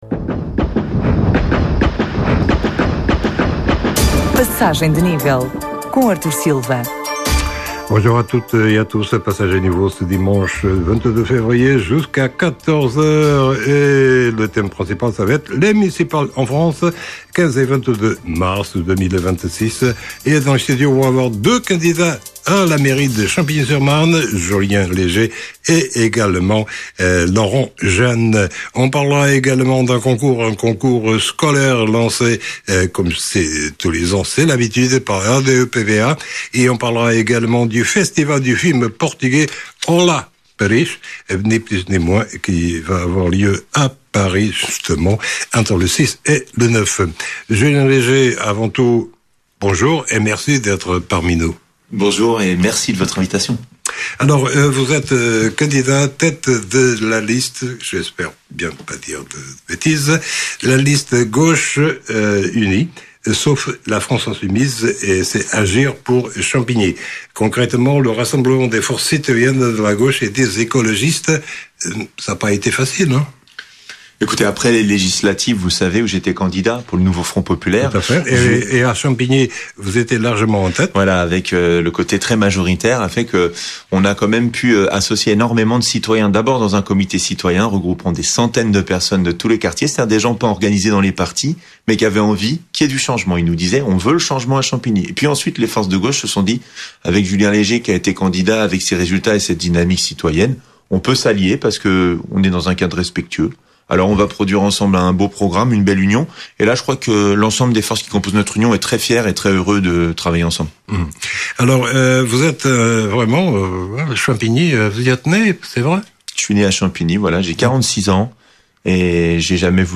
Passagem de Nível, magazine de informação na Rádio Alfa